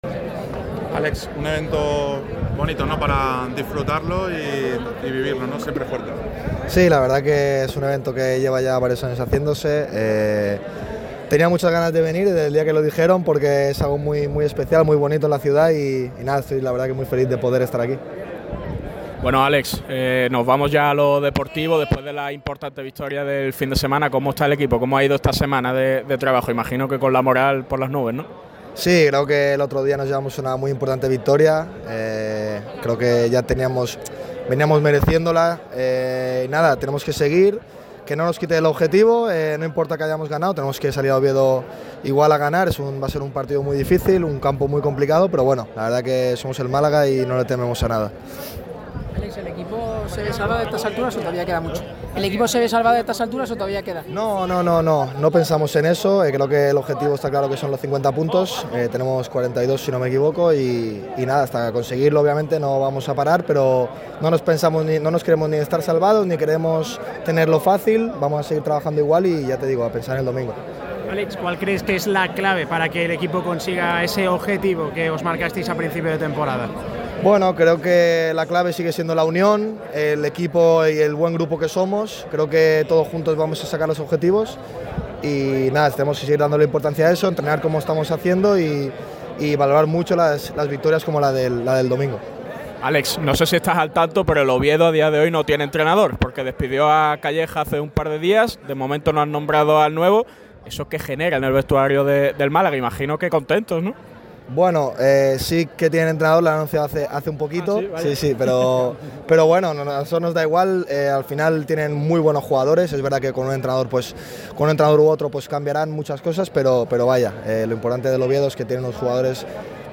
VIII Premios ‘Siempre Fuerte’.